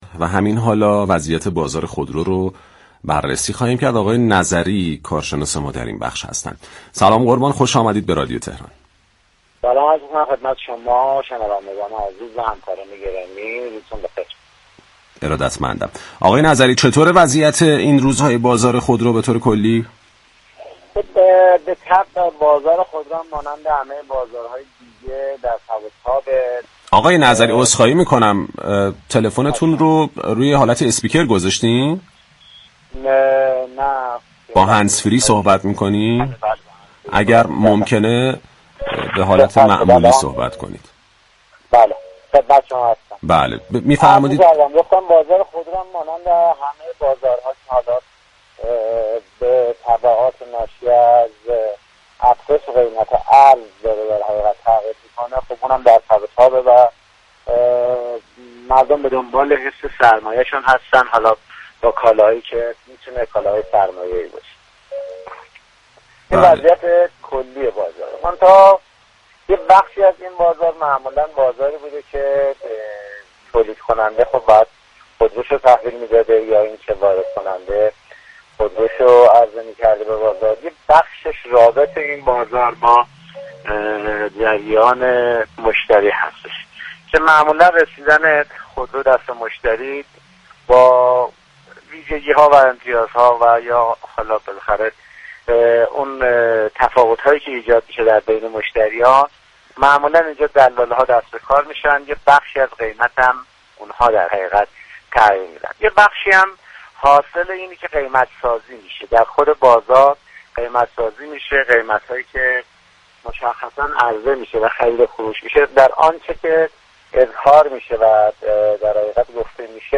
یك كارشناس بازار خودرو گفت:‌ قیمت خودرو به دلیل نوسانات نرخ ارز افزایش پیدا كرده و بازار خودرو ملتهب است؛ خروج خودروهای اقتصادی (تیبا، ساینا، سمند و 405) از چرخه تولید در شرایط ملتهب بازار تصمیم درستی نبود.
در گفت و گو با «بازار تهران»